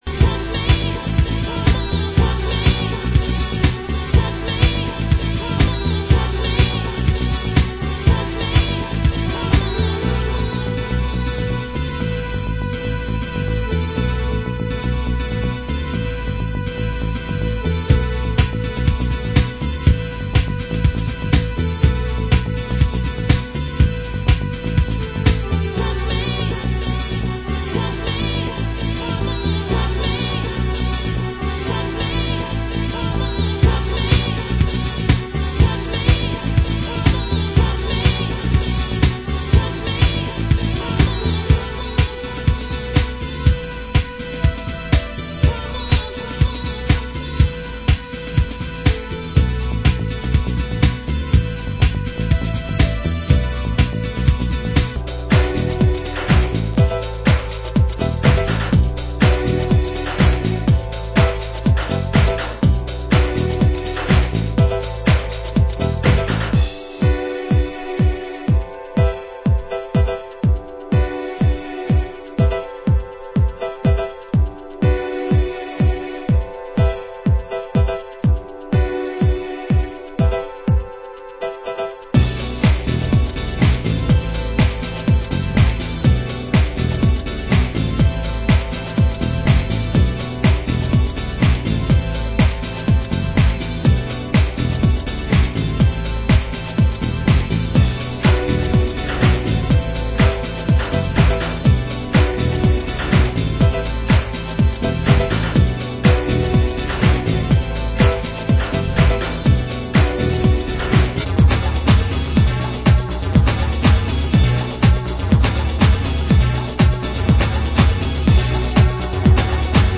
Genre: House